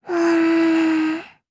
Lilia-Vox-Deny_jp.wav